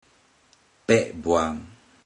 Click each Romanised Teochew word to listen to how the Teochew word is pronounced.
ped01buan0